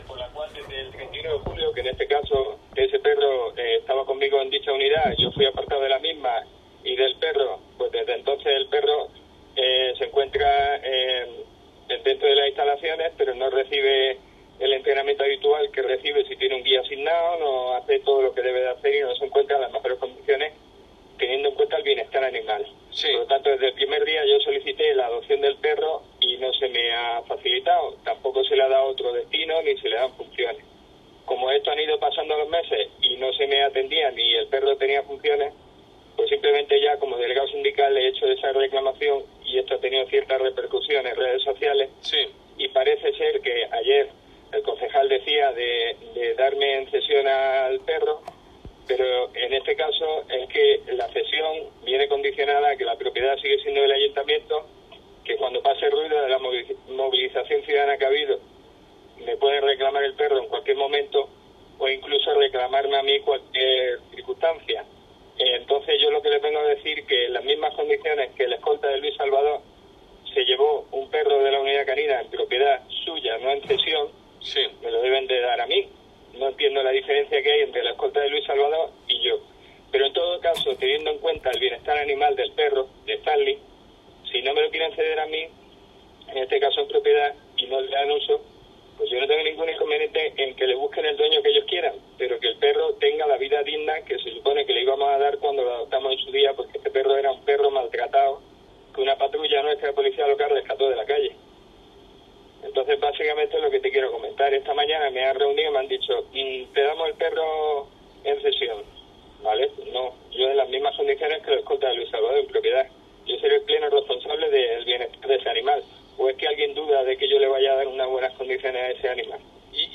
Esta es la entrevista completa, realizada por Canal Sur Radio y TV: